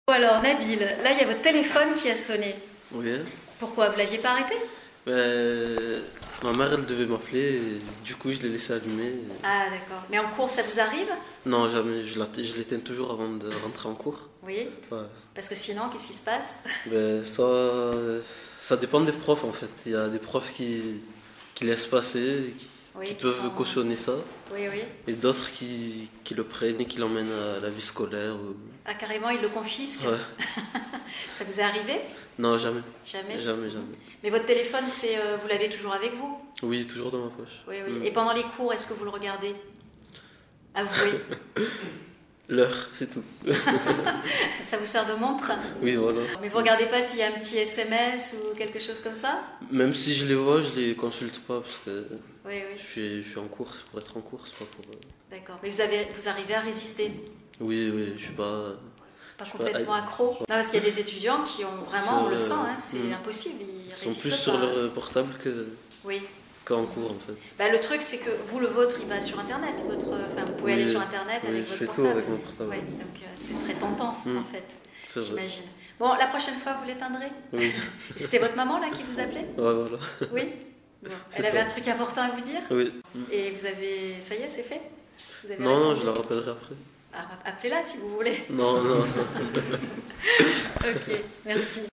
2. Ma mère, elle devait… : tournure très orale et familière, dans laquelle on met deux sujets : le nom et le pronom côte à côte.
On prononce le « s » final dans ce cas.